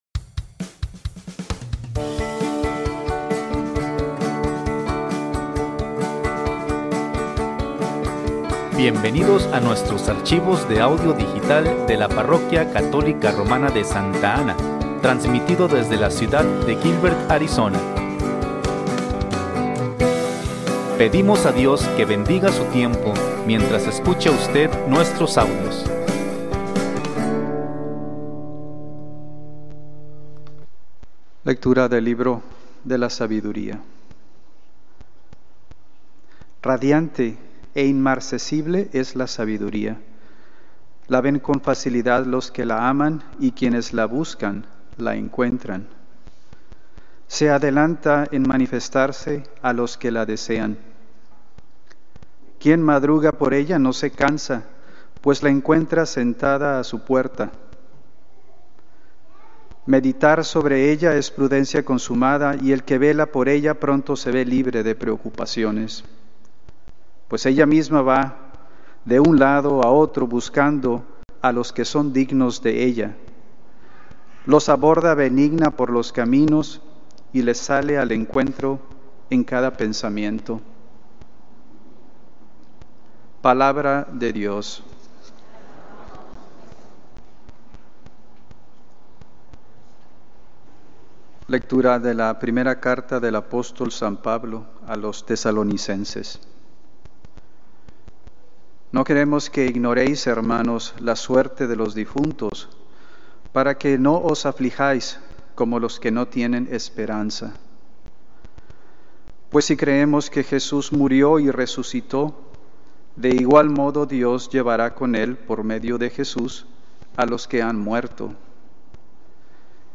Lecturas